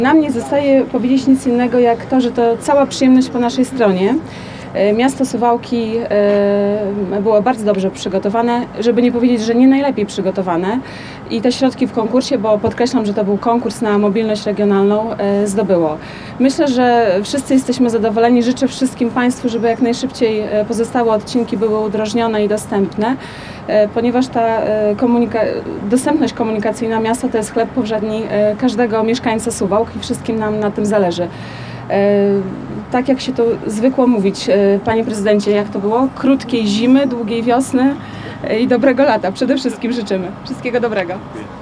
– Dostępność komunikacyjna miasta jest bardzo ważna dla każdego mieszkańca – mówi Anna Naszkiewicz, wicemarszałek województwa podlaskiego.